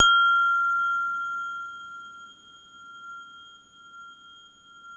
WHINE  F4 -R.wav